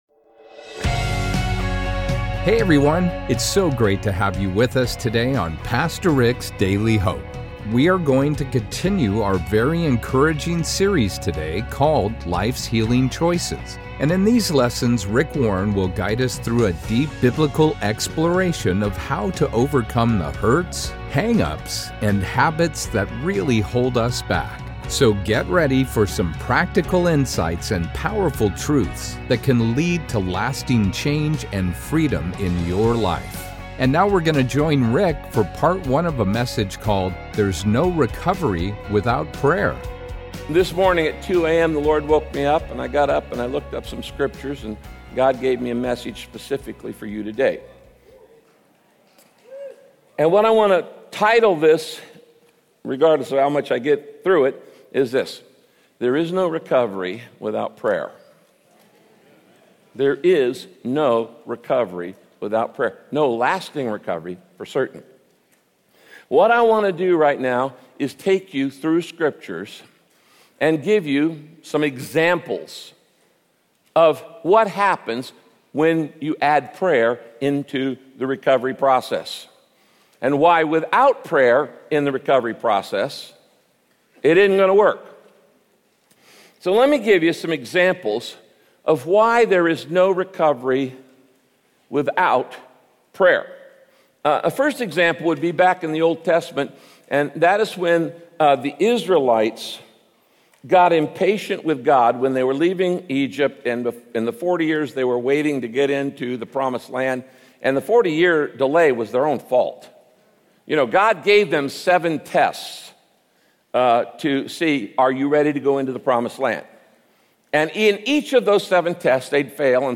1 There's No Recovery Without Prayer - Part 1 Play Pause 14h ago Play Pause Daha Sonra Çal Daha Sonra Çal Listeler Beğen Beğenildi — The Bible teaches that, with prayer, we can recover from the problems we’ve caused by our own impatience. In this broadcast, Pastor Rick explains why God is never in a hurry with your growth.